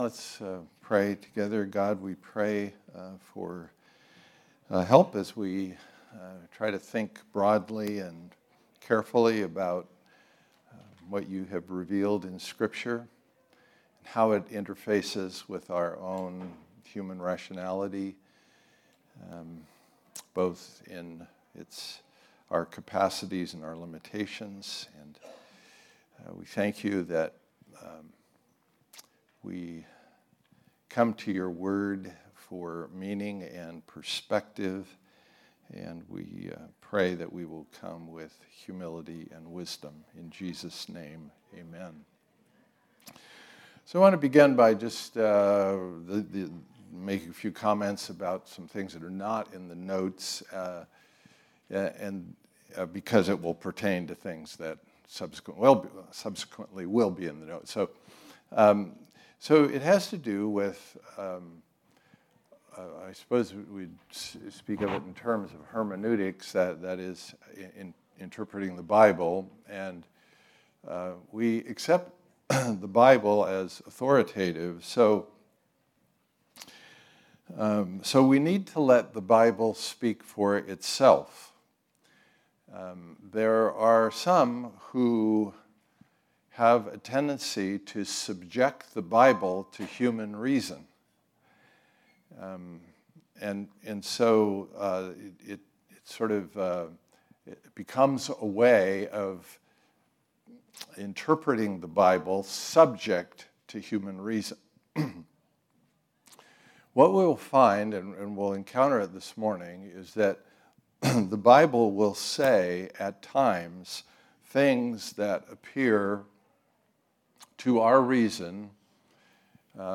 Listen to Message
Type: Sunday School